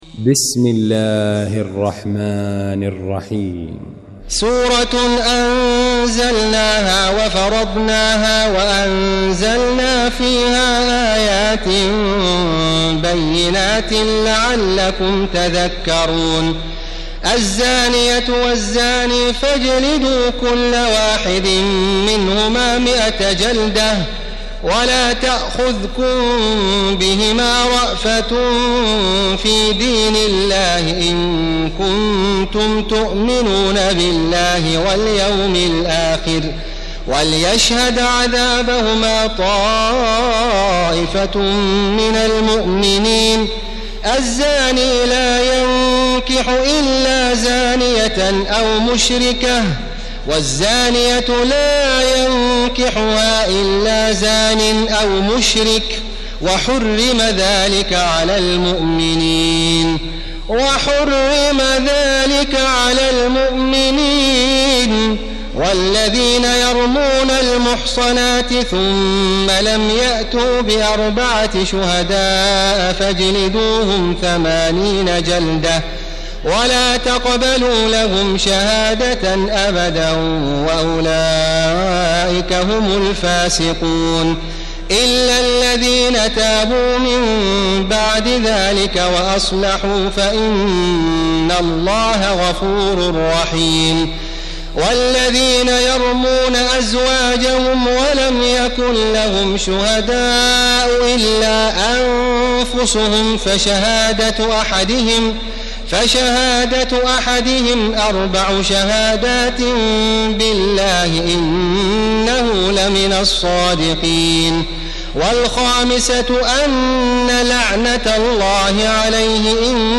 المكان: المسجد الحرام الشيخ: معالي الشيخ أ.د. بندر بليلة معالي الشيخ أ.د. بندر بليلة خالد الغامدي النور